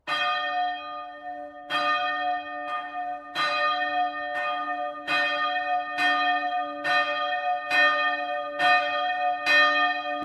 Play Feuerglocke Bimmelt - SoundBoardGuy
Play, download and share Feuerglocke bimmelt original sound button!!!!
feuerglocke-bimmelt.mp3